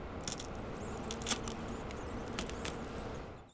楼道场景4.wav